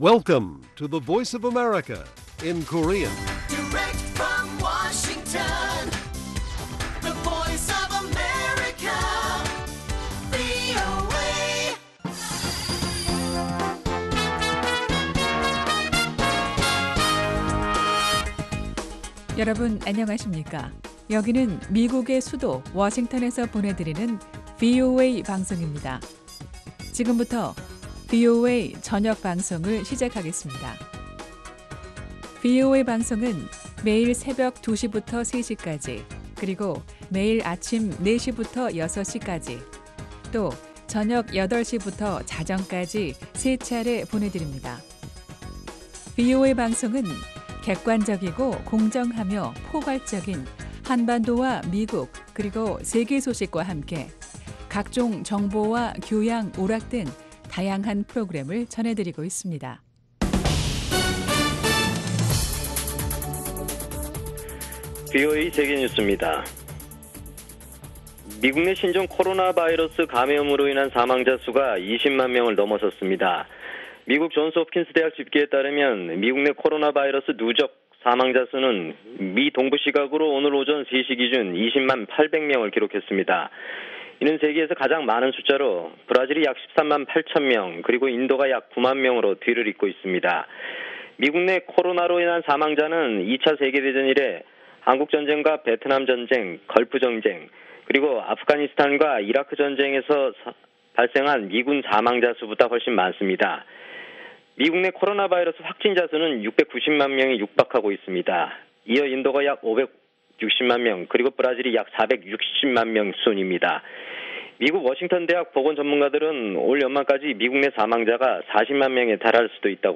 VOA 한국어 간판 뉴스 프로그램 '뉴스 투데이', 2020년 9월 23일 1부 방송입니다. 도널드 트럼프 미국 대통령이 재임 중 4번째 유엔총회 연설에서 처음으로 북한을 언급하지 않았습니다. 문재인 한국 대통령이 유엔총회 연설에서 한반도 종전 선언 카드를 다시 꺼내든 것은 한반도 평화프로세스의 불씨를 살려보겠다는 의지라는 분석이 나왔습니다. 미국의 전문가들이 북한의 이동식 미사일 발사대 (TEL) 역량을 높게 평가하면서, 미국은 정찰과 감시 자산을 통해 수집한 정보를 종합해 실시각으로 추적해야 한다는 지적입니다.